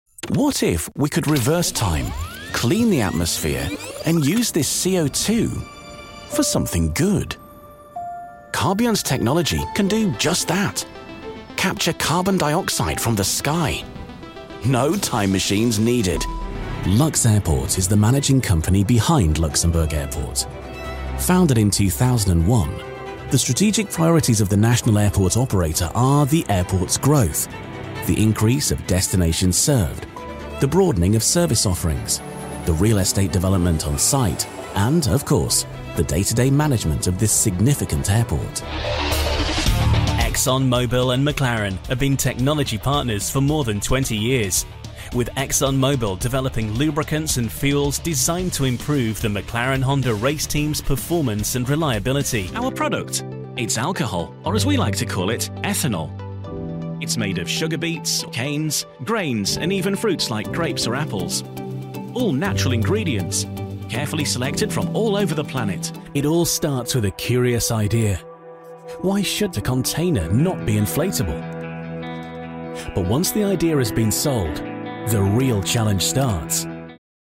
Commercieel, Stoer, Vriendelijk, Warm, Zakelijk
Corporate
Fris, warm, zacht, vriendelijk en conversatieel
Energieke verkoop en indrukwekkende diepe stem
Zowel neutraal Noord-Engels als een echt Yorkshire accent (West Yorkshire/Leeds)